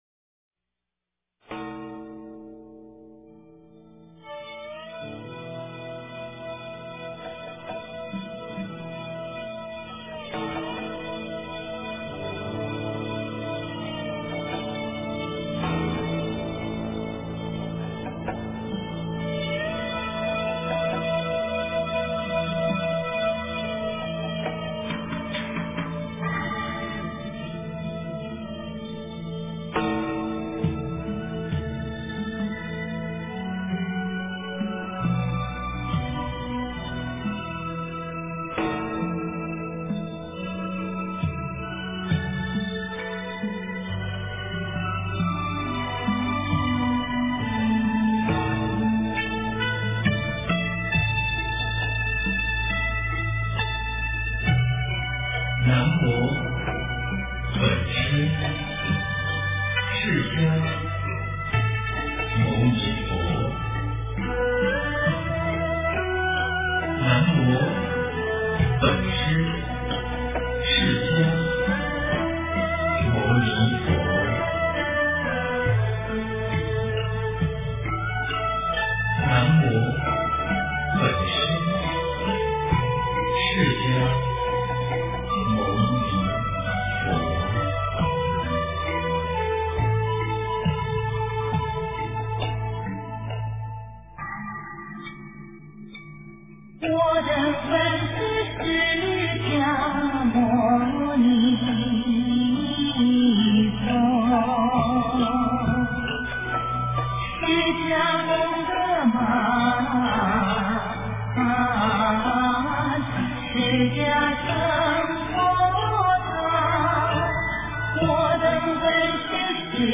南无本师释迦牟尼佛--浴佛颂 经忏 南无本师释迦牟尼佛--浴佛颂 点我： 标签: 佛音 经忏 佛教音乐 返回列表 上一篇： 普贤行愿品--普寿寺 下一篇： 大悲咒.念诵--普寿寺 相关文章 由心的旋律Free Melody--瑜伽静心曲 由心的旋律Free Melody--瑜伽静心曲...